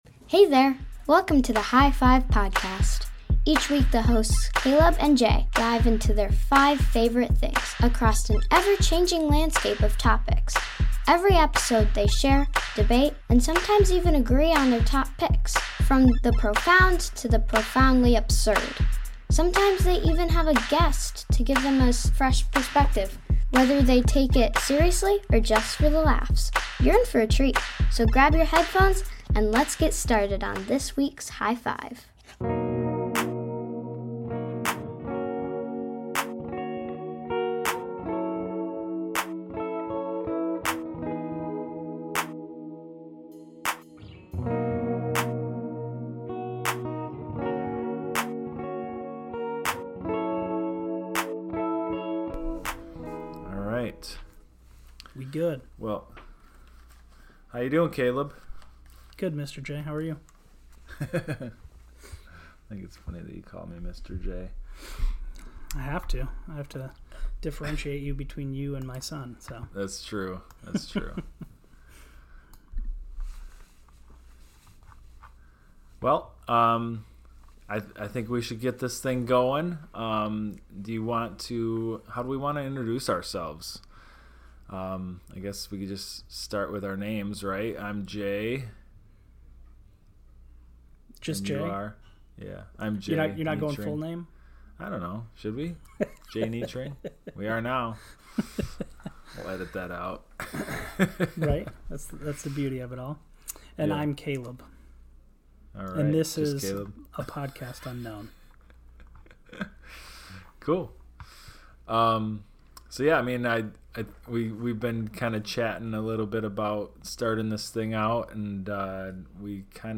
From the deeply meaningful to the delightfully ridiculous, they share, debate, and occasionally agree on their top picks. With occasional guests to provide new insights, the show blends serious discussion with laughter.